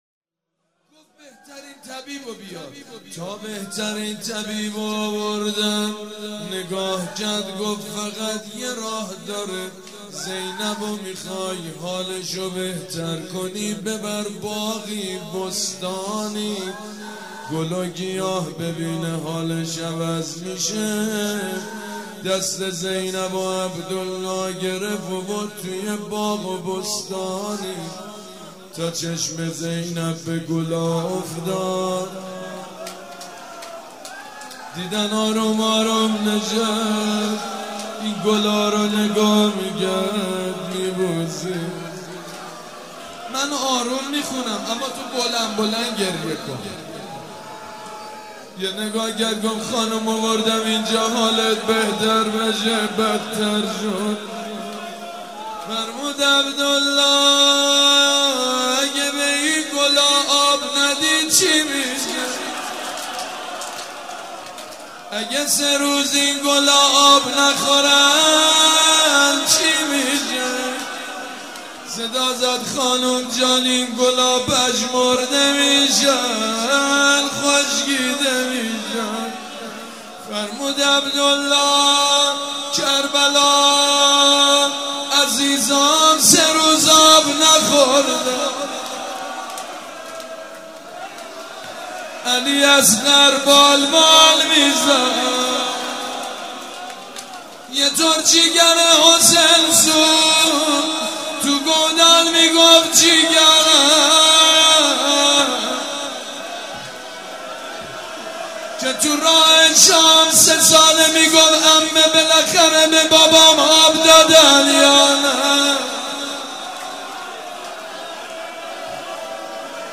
مداح : سیدمجید بنی‌فاطمه قالب : روضه